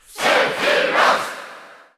File:Sephiroth Cheer Italian SSBU.ogg
Crowd cheers (SSBU) You cannot overwrite this file.